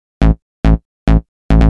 Techno / Bass / SNTHBASS087_TEKNO_140_A_SC2.wav